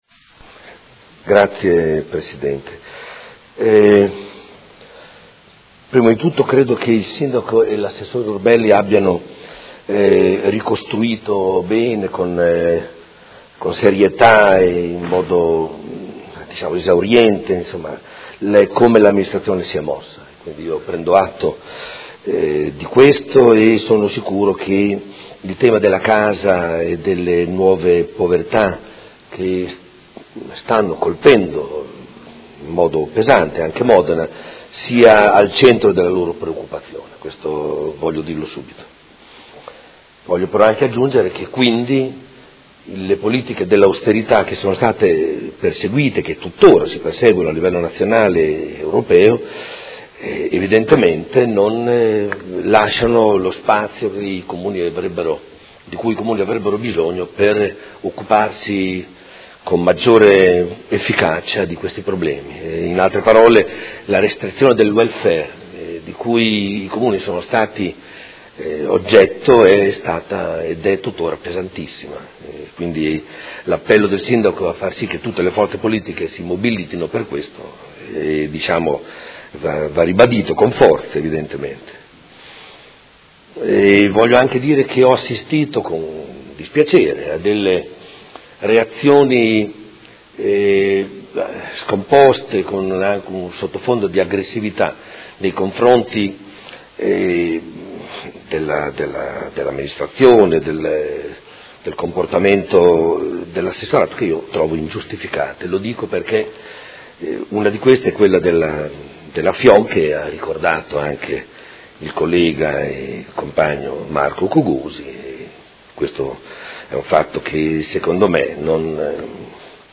Seduta del 19/05/2016. Interrogazione del Gruppo Per Me Modena avente per oggetto: Sgomberi degli stabili occupati e questione abitativa a Modena.